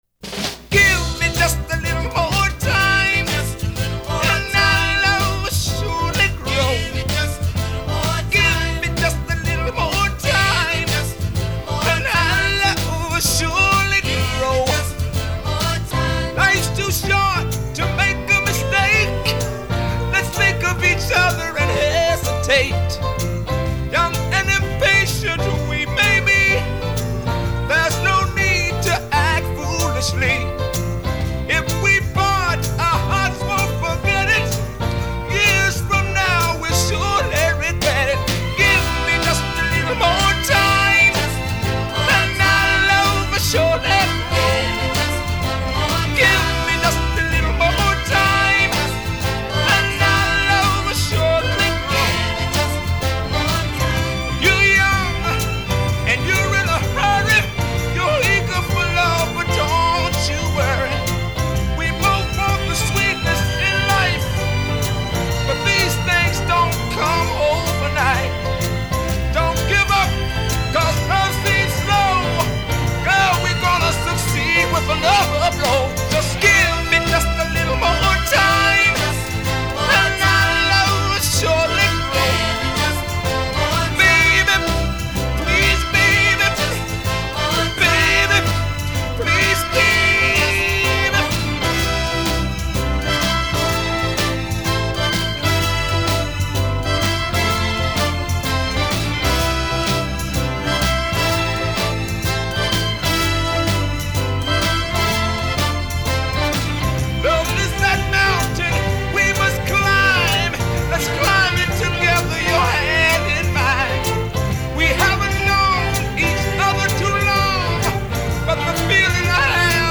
piazza un vecchio classico Motown